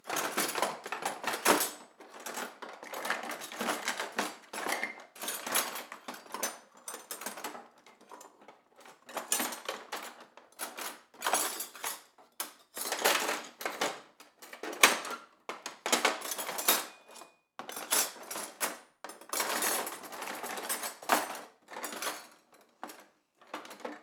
Cutlery in Drawer Sound
household